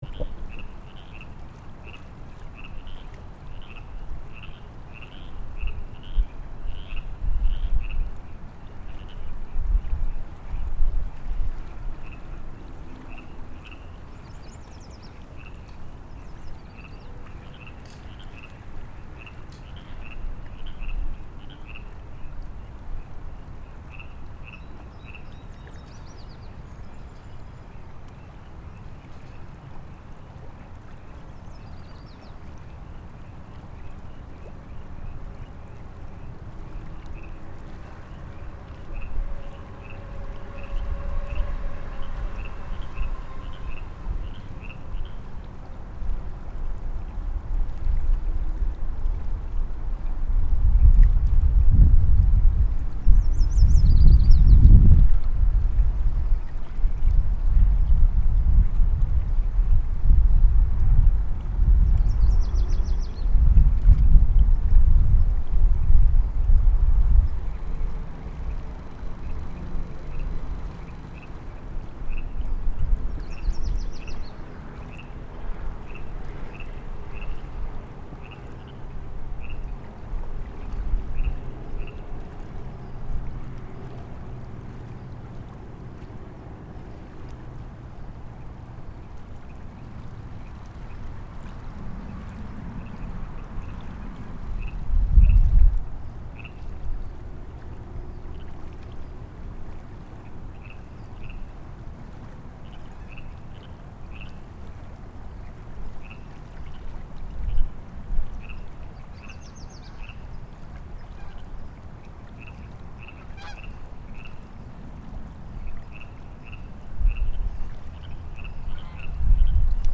This is a method of recording sound that uses two microphones, arranged with the intent to create a 3-D stereo sound sensation for the listener of actually being in the environment that was captured.
“I made it around dusk as I wanted to get the call of the Baja California frogs that live on this stretch of the river… it’s a part of the river which hasn’t completely been trapped in concrete as the water table is too high, and as such, the concrete bottom was never able to stick here.